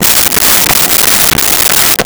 Saw Wood 03
Saw Wood 03.wav